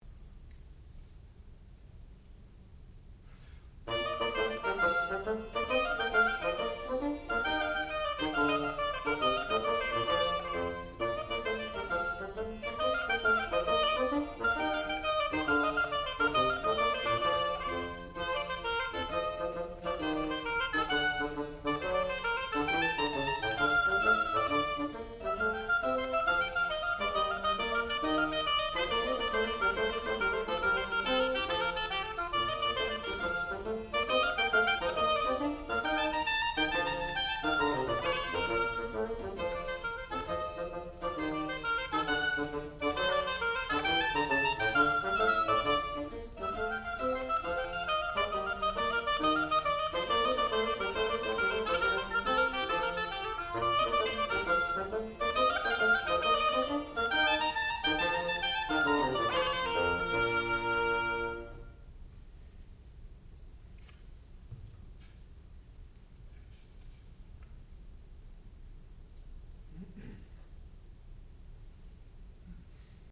Partita No.1 in B Flat for oboe and continuo
classical music
harpsichord